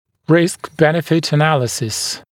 [rɪsk-‘benɪfɪt ə’næləsɪs][риск-‘бэнифит э’нэлэсис]анализ соотношения рисков и выгоды